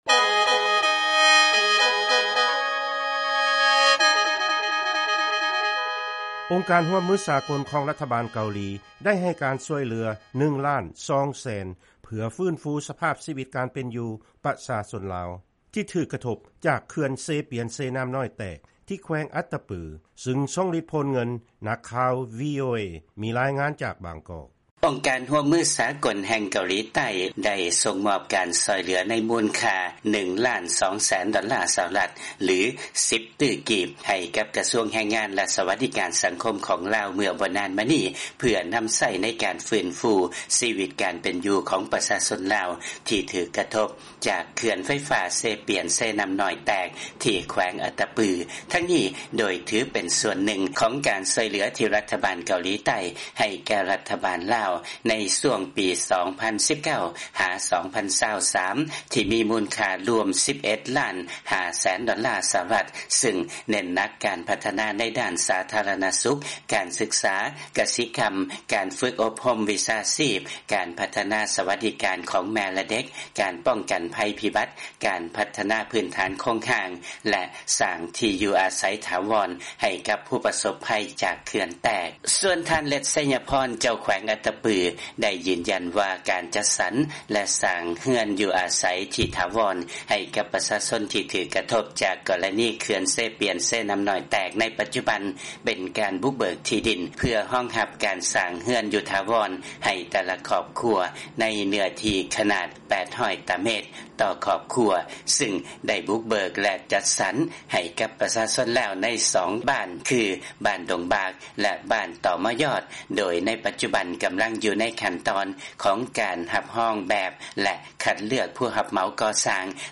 ຟັງລາຍງານ ອົງການ KOICA ໃຫ້ການຊ່ວຍເຫຼືອ ປະຊາຊົນ ທີ່ຖືກກະທົບ ຈາກເຂື່ອນເຊປຽນ-ເຊນ້ຳນ້ອຍແຕກ